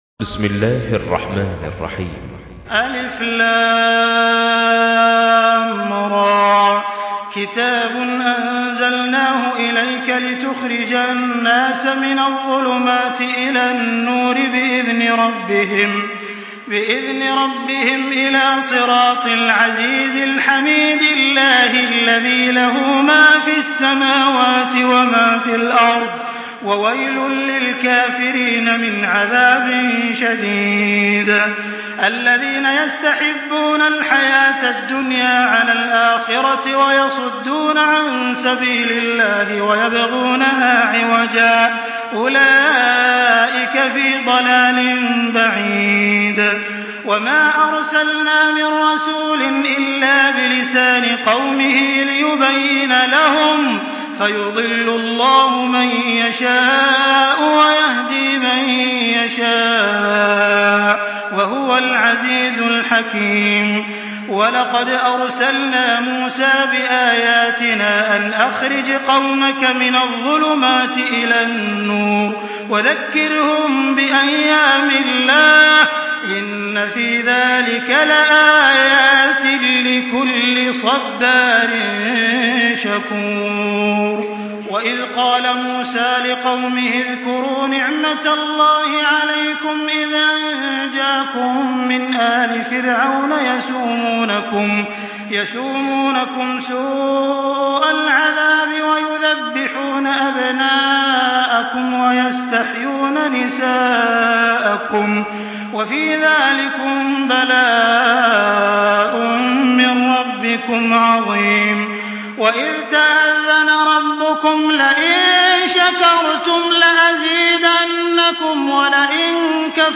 Makkah Taraweeh 1424
Murattal